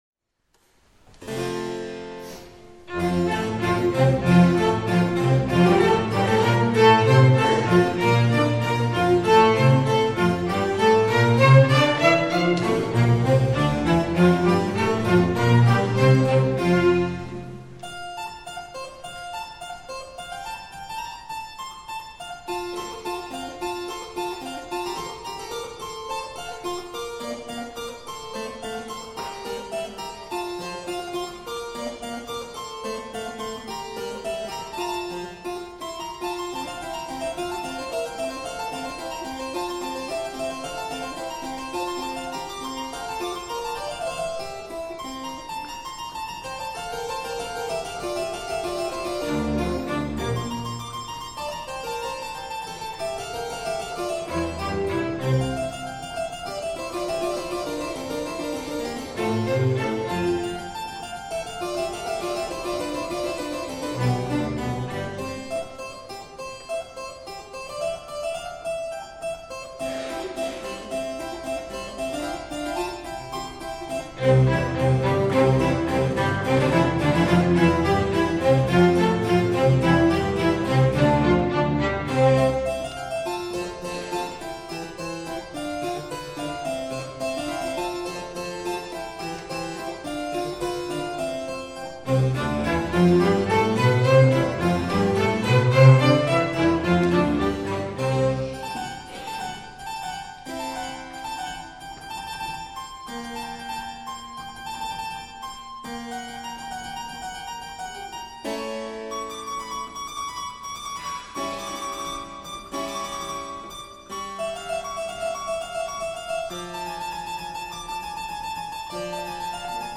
Heute und hier hören Sie zwei barocke Instrumentalkonzerte, die 2019 und 2016 in St. Josef aufgenommen wurden.
Viola
Violoncello
Kontrabass
Cembalo
Konzert für Tasteninstrument und Streichorchester
Händel-Cembalokonzert.mp3